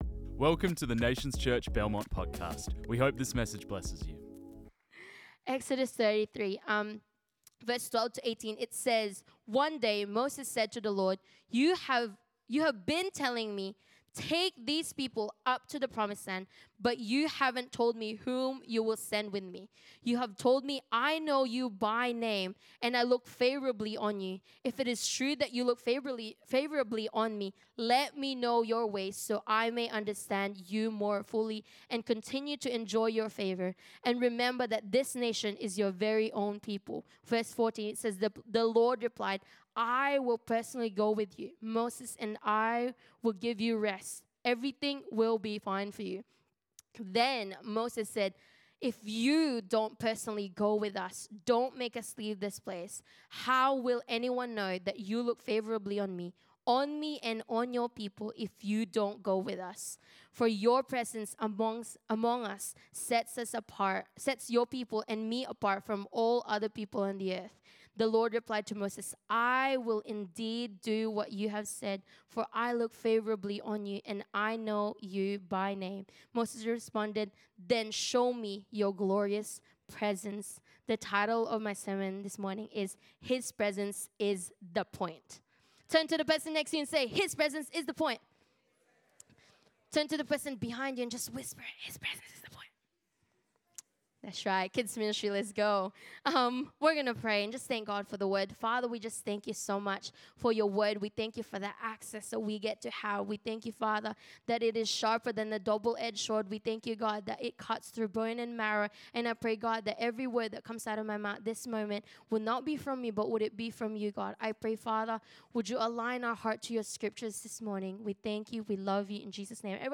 This message was preached on 04 May 2025.